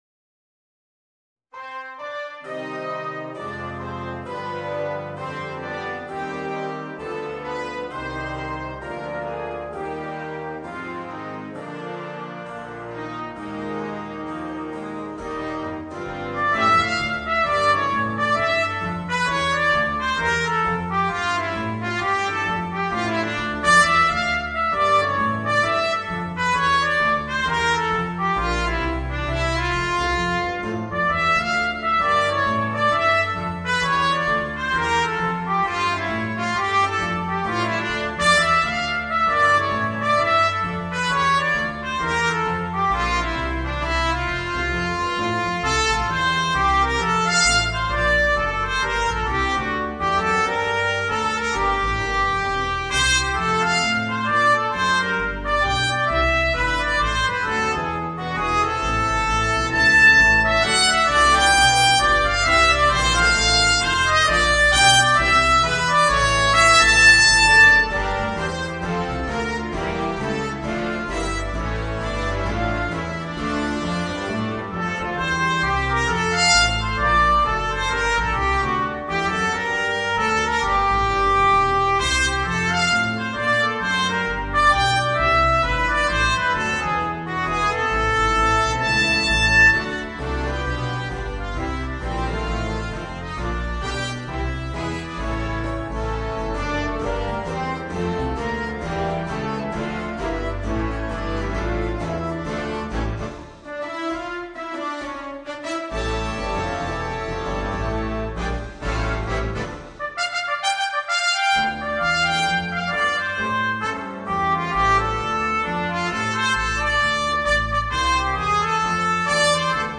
Voicing: Oboe and Concert Band